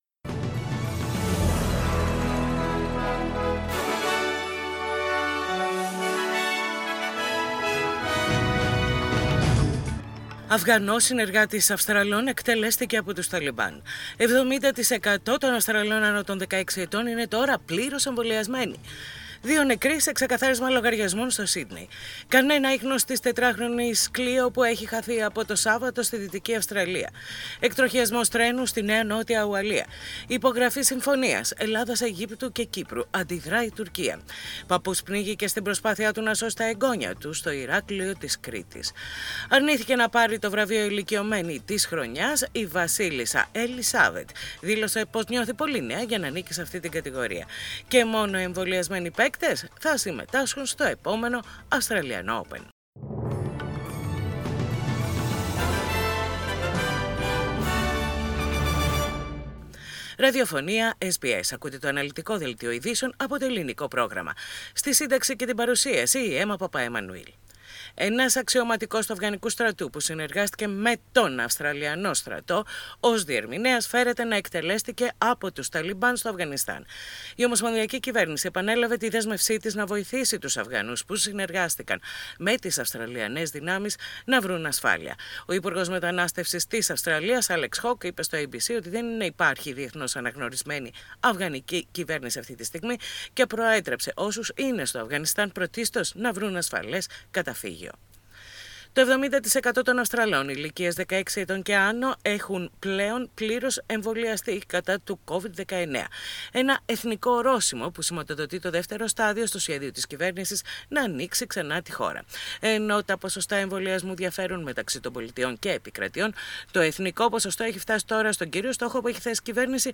Ειδήσεις στα Ελληνικά - Τετάρτη 20.10.21